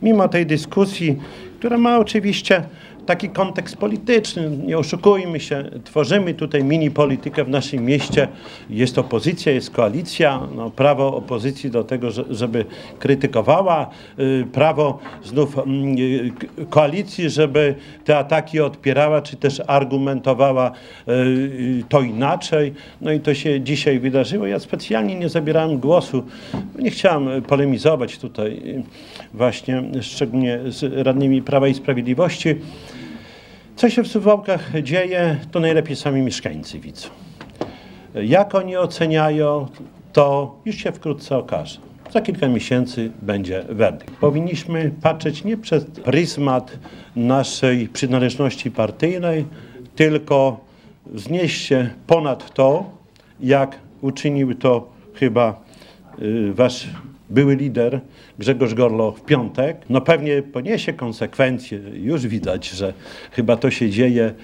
Po głosowaniu Czesław Renkiewicz, prezydent Suwałk, dyskusję ocenił jako polityczną. Dodał, że ocena pracy samorządu będzie za kilka miesięcy, natomiast teraz cieszy się z pozytywnych opinii mieszkańców.